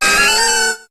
Cri de Girafarig dans Pokémon HOME.